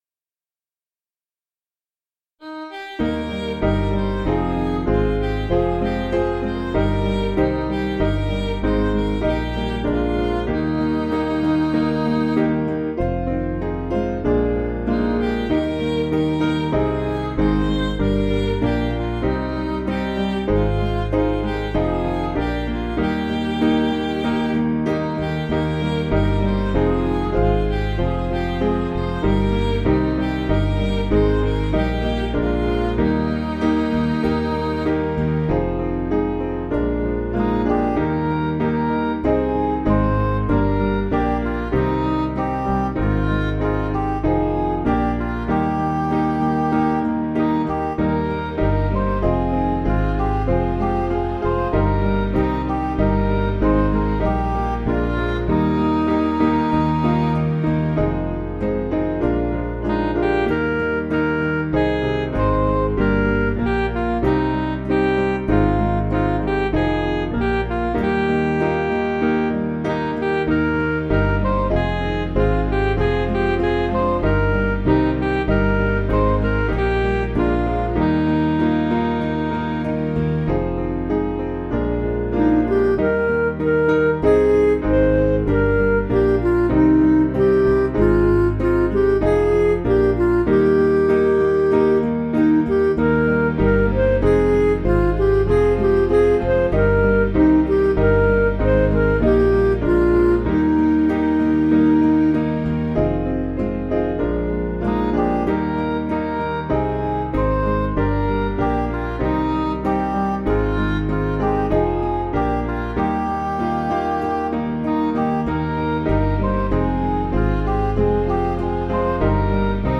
8.6.8.6.D
Piano & Instrumental
(CM)   6/Eb
Midi